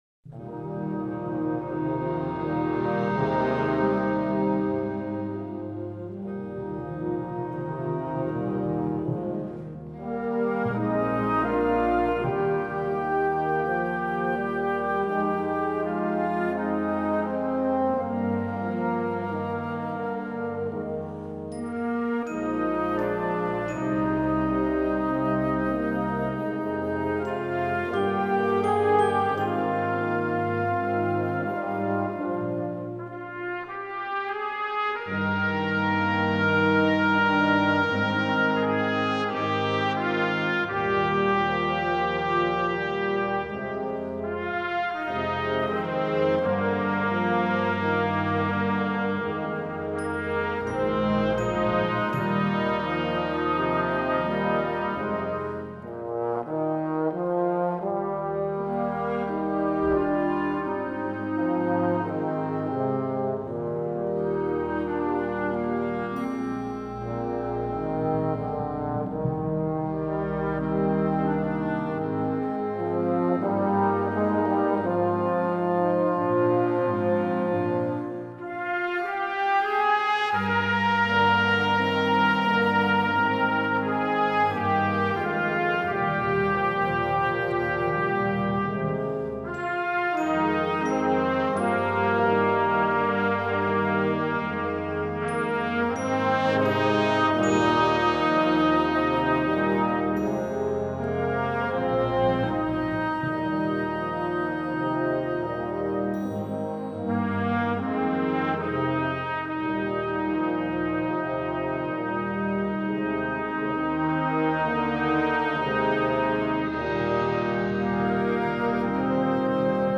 Instrumentation: concert band
folk, traditional, classical, instructional, children